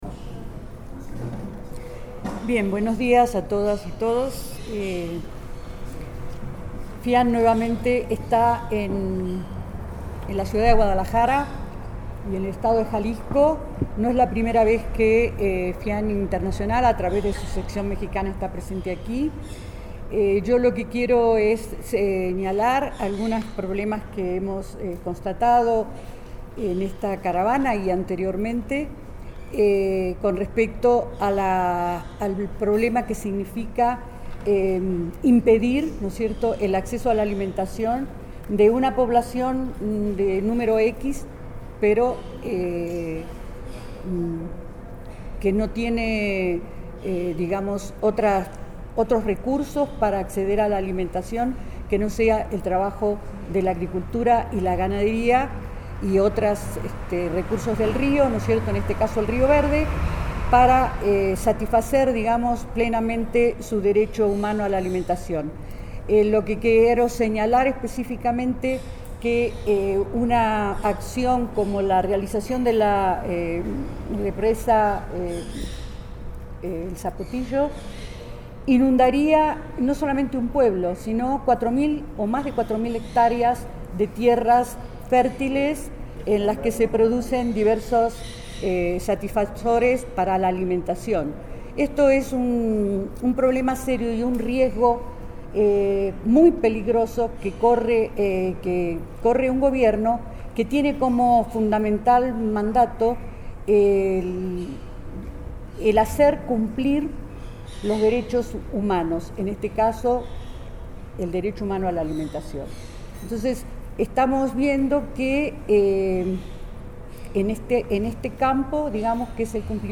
En conferencia de prensa se dio a conocer el resultado de la Auditoría popular a la presa el Zapotillo que afectará a las comunidades de Temacapulin, Palmarejo y Acasico en la que se señala la violación sistemática a los Derechos Humanos, las irregularidades en la construcción de la presa el Zapotillo, el posible desacato de la sentencia emitida por la Suprema Corte de Justicia de la Nación (SCJN), el mal manejo del agua en el estado de Jalisco y se exigió al presidente Enrique Peña Nieto cumplir los derechos de los pueblos originarios dejando de lado la política extractivista que tiene consecuencias irreparables al medio ambiente.